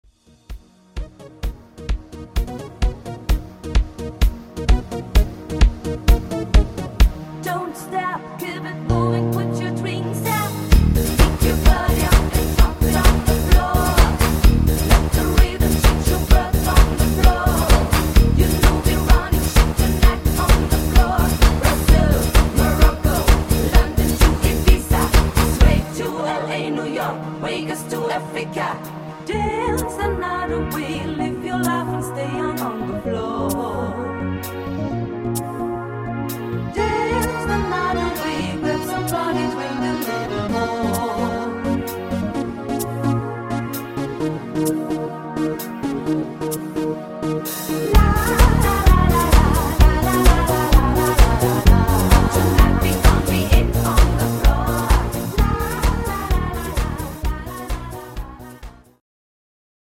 No Rap Version